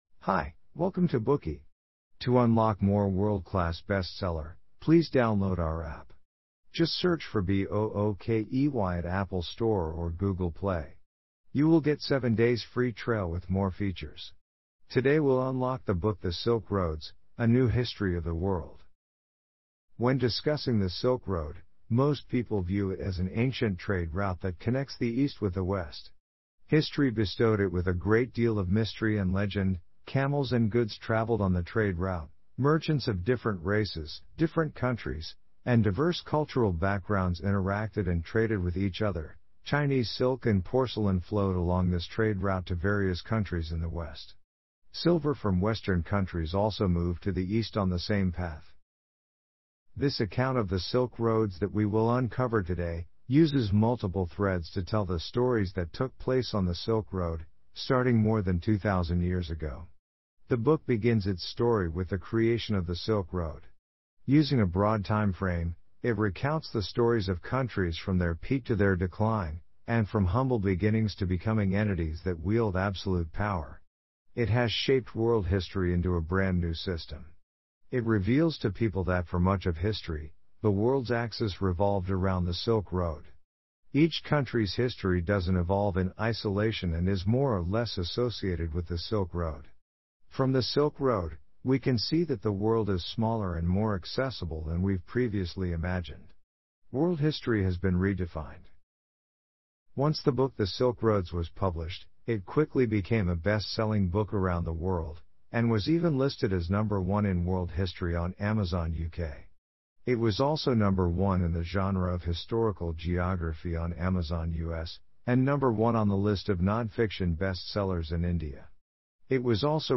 The Silk Roads Full Free Audio Book Summary